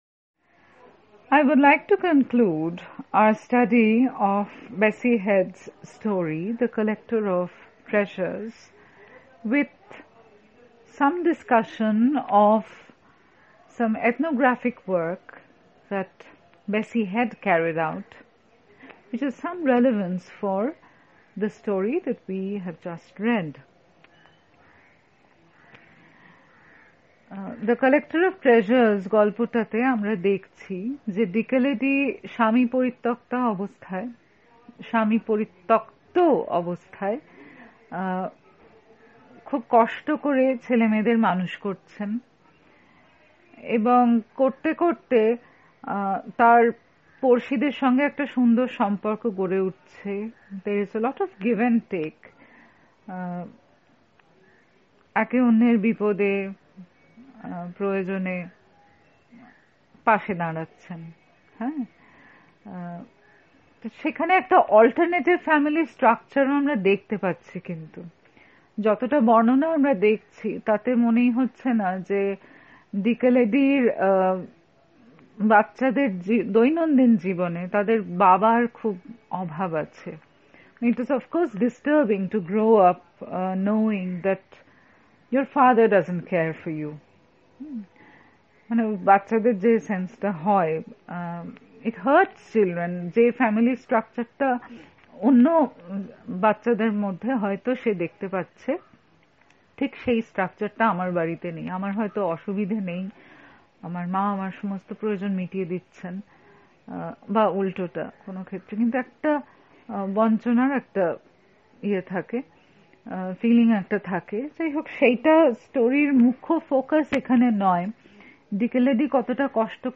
DB Lec 6 The breakdown of family life.mp3